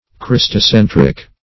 Search Result for " christocentric" : The Collaborative International Dictionary of English v.0.48: Christocentric \Chris"to*cen"tric\, a. [Christ + centric.]